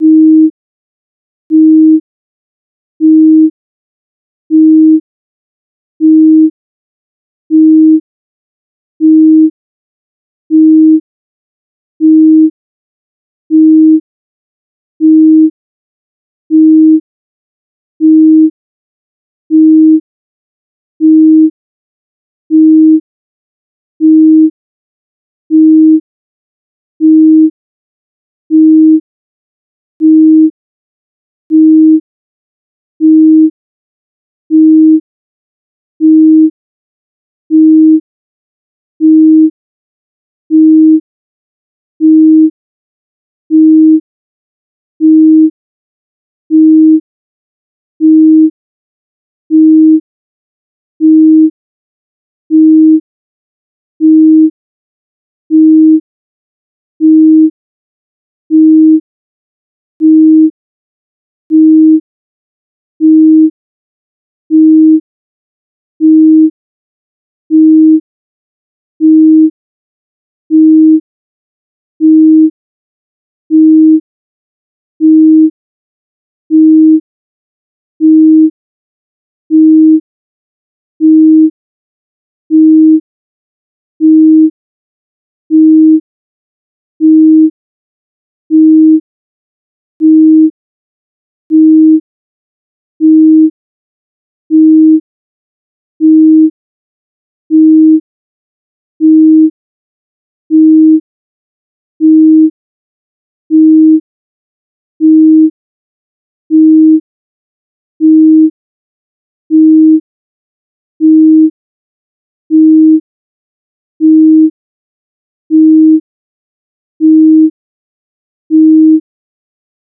Надо кратковременно включать звук на 1 сек, потом пауза (в это время крутить гейн), опять пробовать... и т.д. Вот для этого я и сделал такой прерывистый тон.